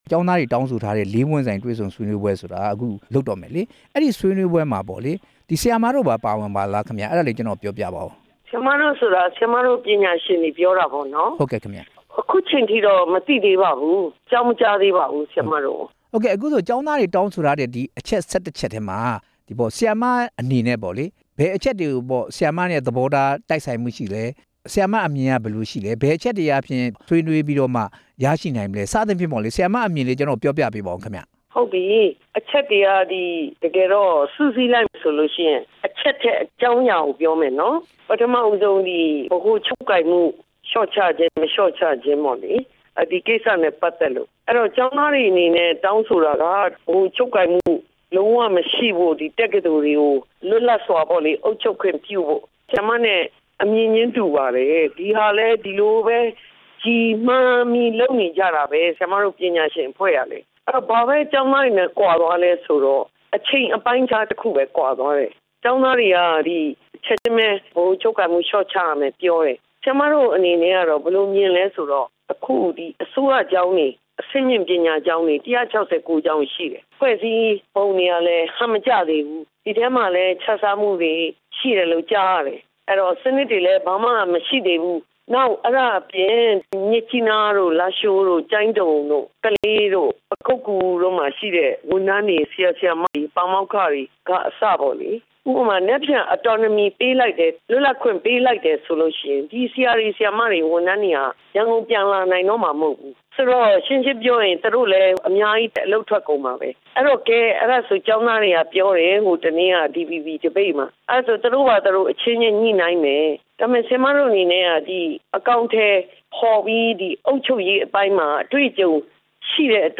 ကျောင်းသားဆန္ဒပြပွဲတွေအပေါ် ပညာရေးအကြံပေးအဖွဲ့ခေါင်းဆောင် ဒေါက်တာဒေါ်ရင်ရင်နွယ်နဲ့ မေးမြန်းချက်